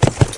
PixelPerfectionCE/assets/minecraft/sounds/mob/horse/gallop1.ogg at mc116
gallop1.ogg